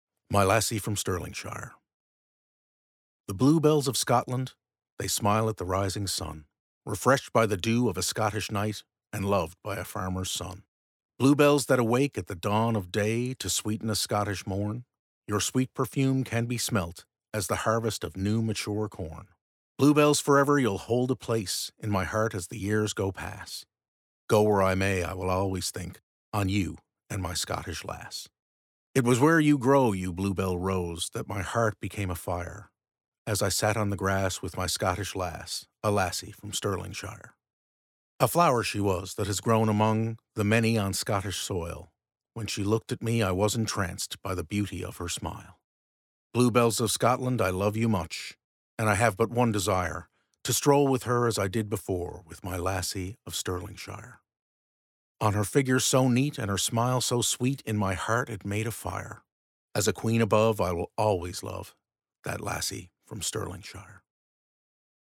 Poem_My-Lassie-From-Sterlingshire.mp3